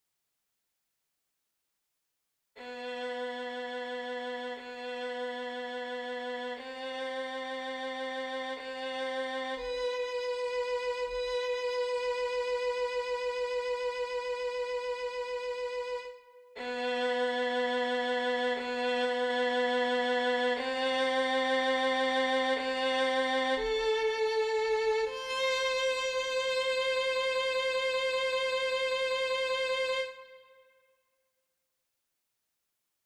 Key written in: C Major